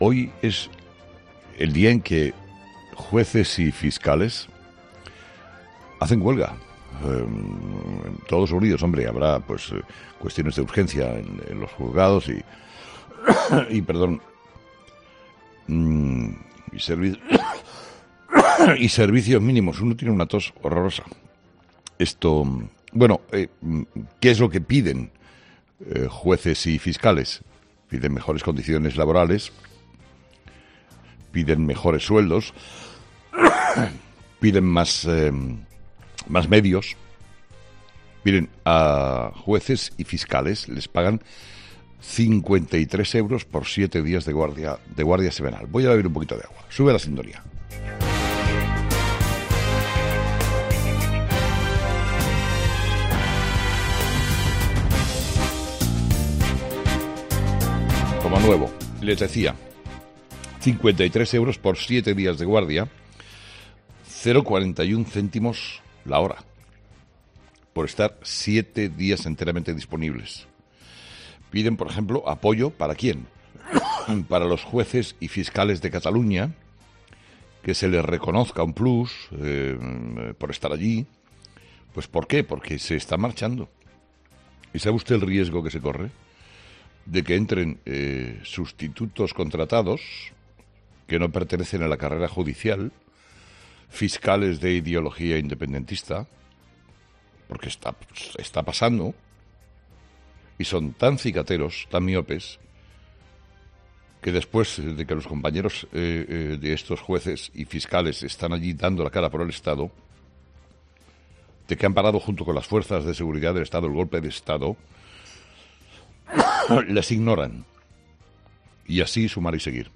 ESCUCHA EL MONÓLOGO COMPLETO DE HOY DE CARLOS HERRERA